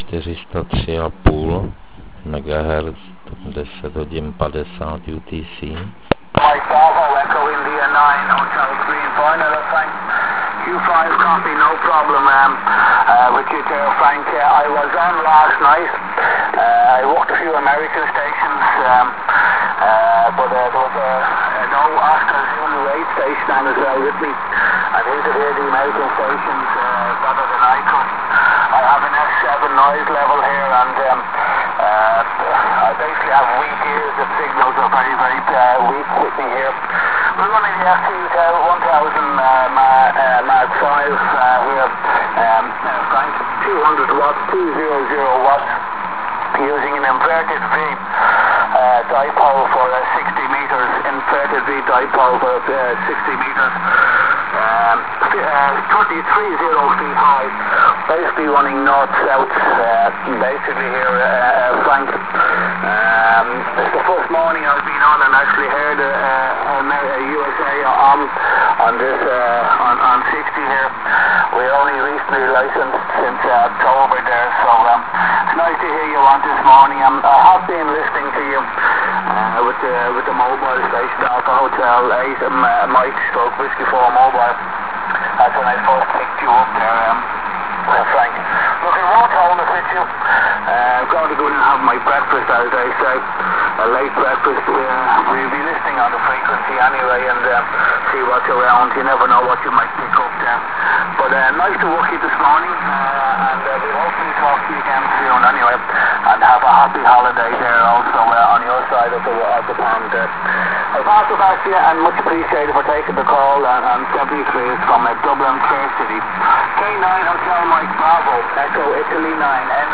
Včera mi to nedalo a malinko jsem na těchto kmitočtech se zaposlouchal.